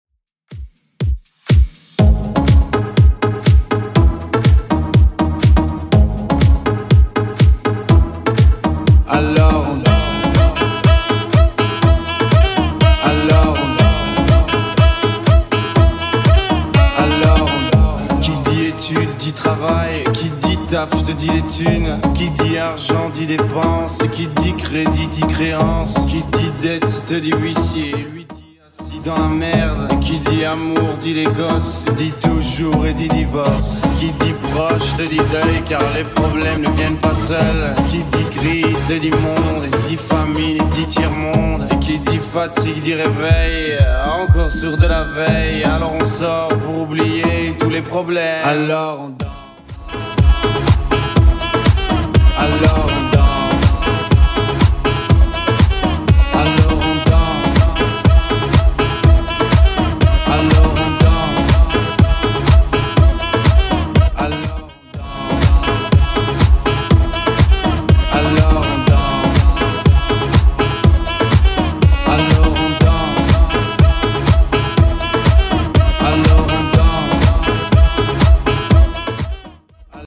Genre: 70's